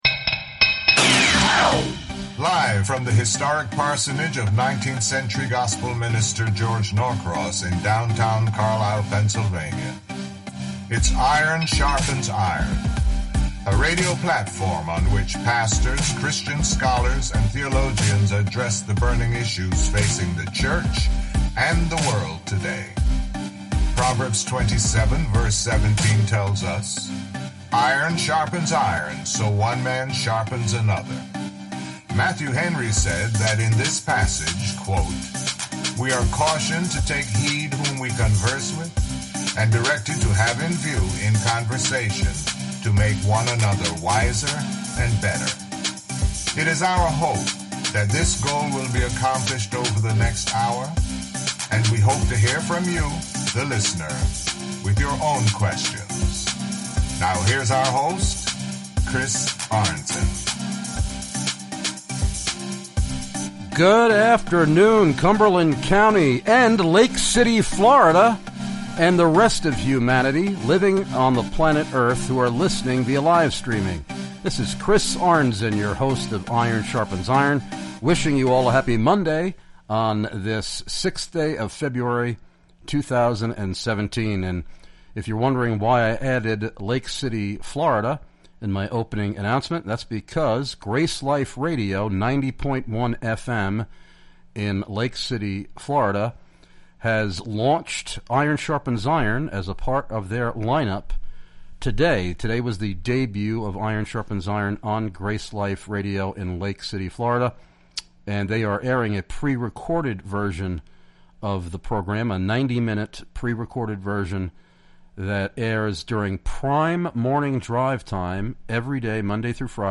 As a result, you will notice some background conference noise–hopefully it will help to convey the excitement at this year’s convention!